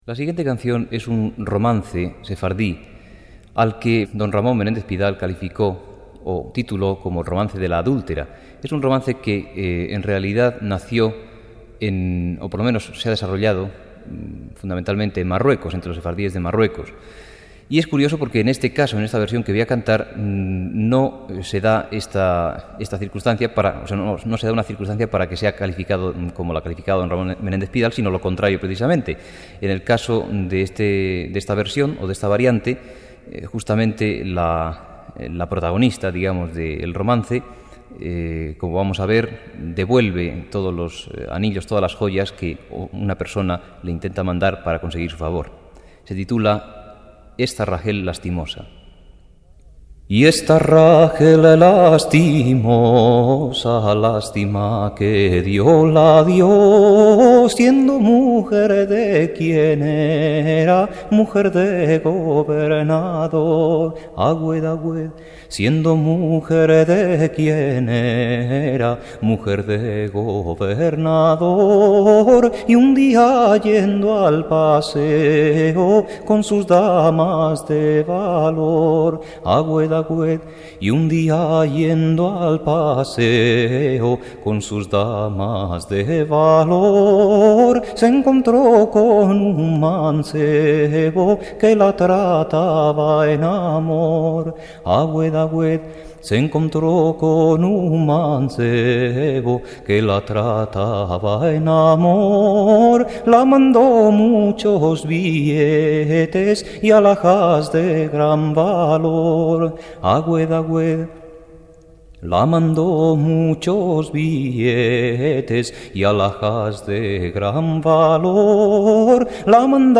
Intérpretes: Joaquín Díaz